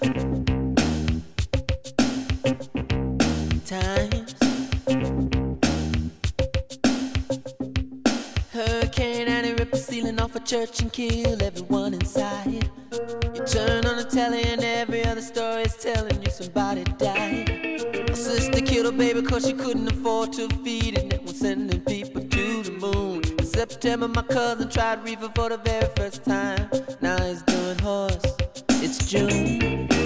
"templateExpression" => "Rhythm'n'blues, soul"